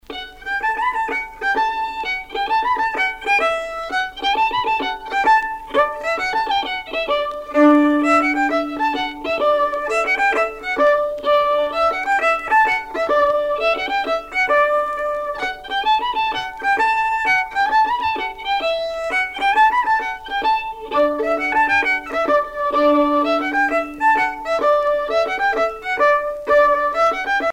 danse : gâtinelle
Pièce musicale éditée